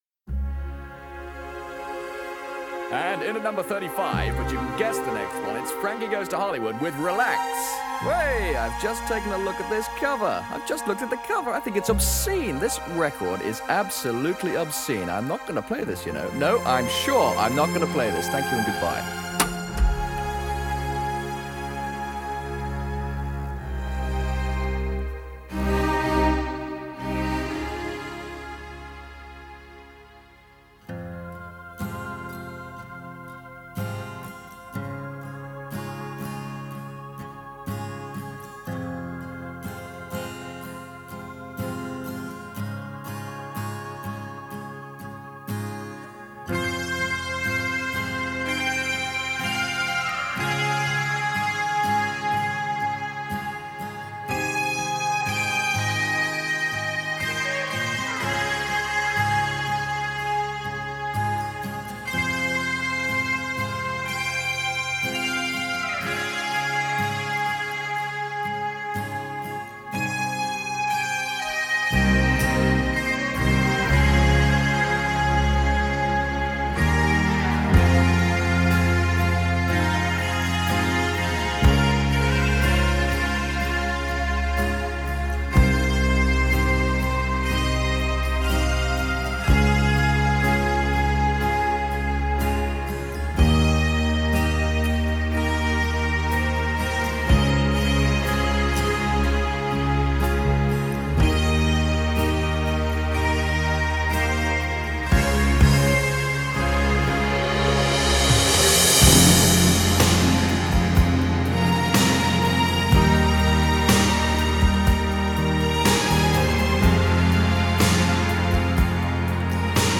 …piano.